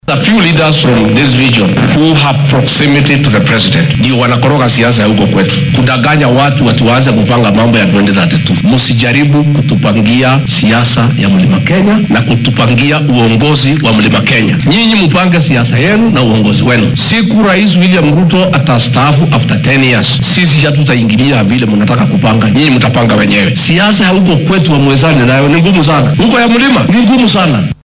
Madaxweyne ku xigeenka dalka Rigathi Gachagua ayaa sheegay in ay jiraan siyaasiyiin ka soo jeedo gobolka Rift Valley oo aad ugu dhow madaxweyne William Ruto kuwaasi oo isku dayaya in ay farageliyaan arrimaha siyaasadda ee gobolka Bartamaha dalka. Xilli uu ku sugnaa ismaamulka Uasin Gishu ayuu madaxdan oo uunan magacdhawin u sheegay in aynan ku mashquulin hoggaanka siyaasadeed ee Mt. Kenya.